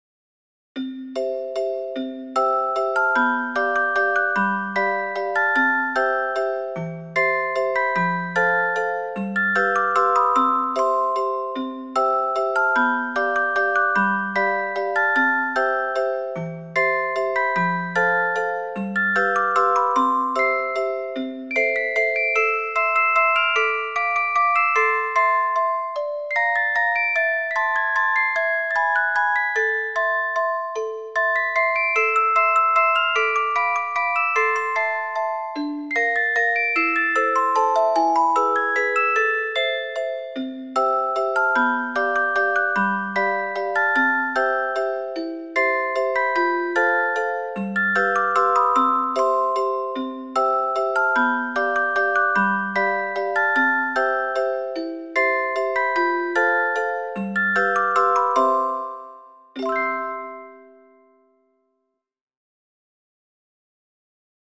クラシック曲（作曲家別）－MP3オルゴール音楽素材